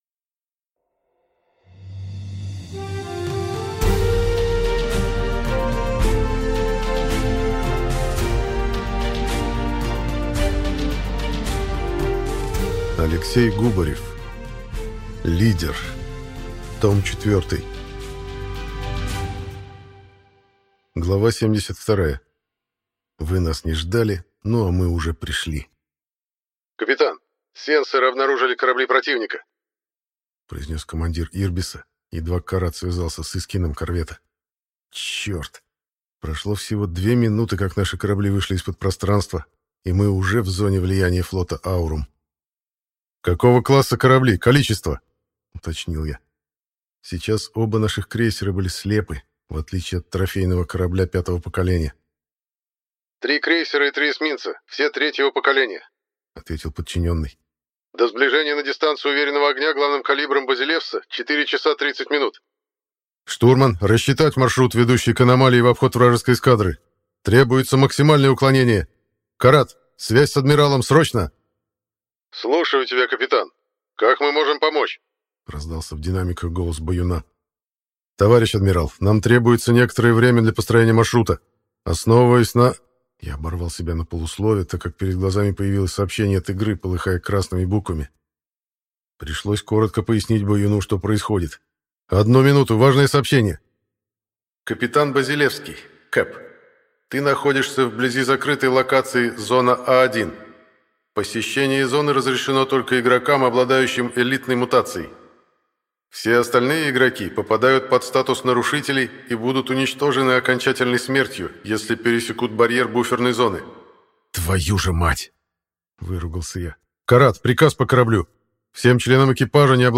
Аудиокнига Лидер | Библиотека аудиокниг
Прослушать и бесплатно скачать фрагмент аудиокниги